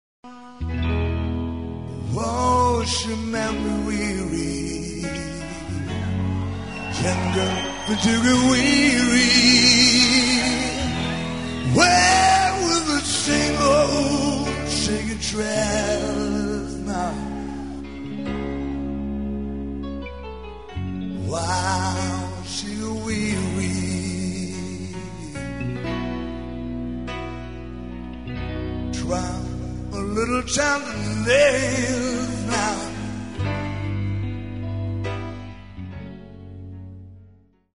CD-LIVE